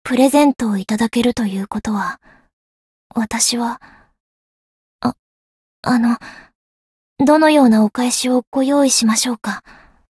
灵魂潮汐-德莱洁恩-情人节（送礼语音）.ogg